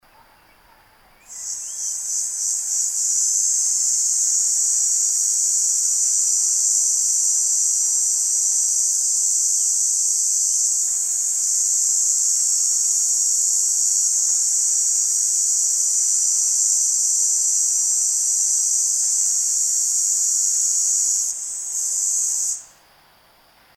HOME - Homopteran Audio Samples - HOME
Canta dalle ore centrali del giorno fino a dopo il tramonto, con intenso e sottile ronzio, e un caratteristico innesco del canto con da due a quattro sillabe iniziali.
Sings during the day from the central hours until after sunset, with intense and high-pitched buzz, and a characteristic beginning of the song with from two to four initial syllables.
Tibicina_haematodes.mp3